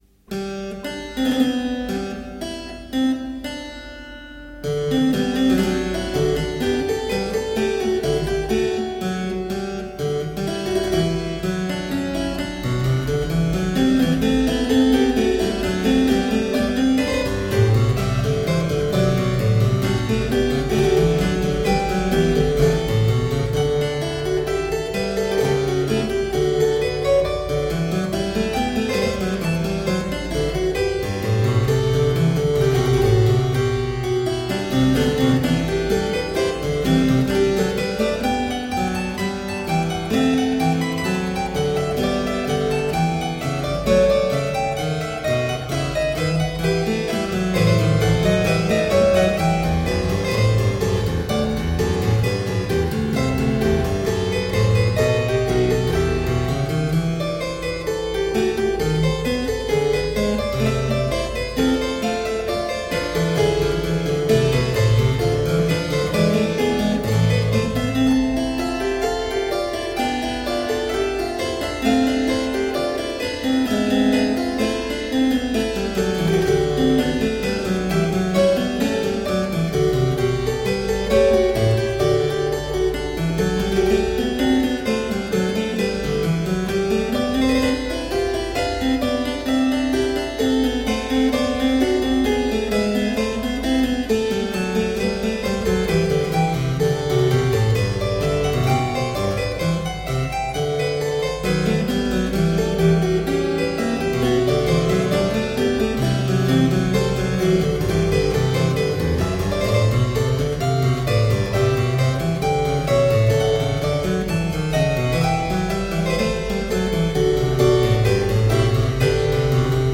Solo harpsichord music.